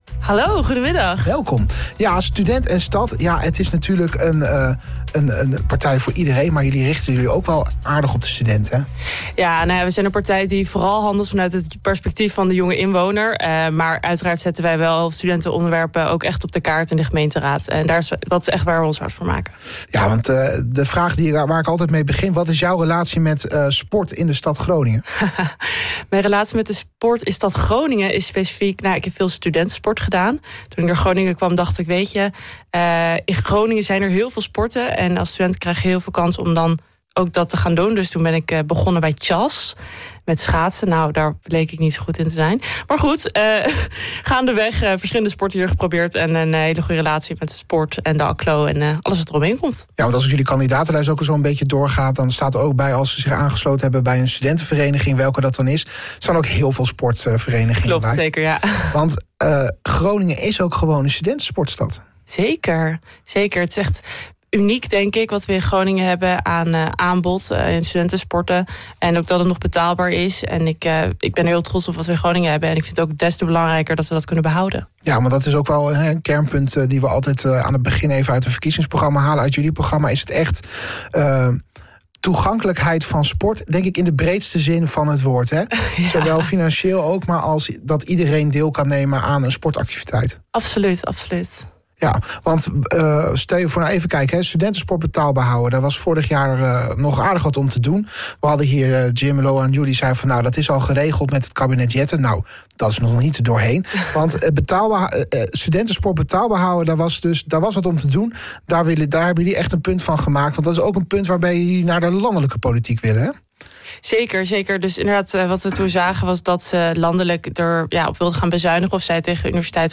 Verkiezingsgesprek: Stadspartij ziet toekomst FC in Meerstad; Student & Stad wil meer ruimte voor studentensport
In de aanloop naar de gemeenteraadsverkiezingen op woensdag 18 maart organiseert OOG Sport een reeks verkiezingsgesprekken.